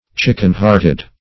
\Chick"en-heart`ed\, a.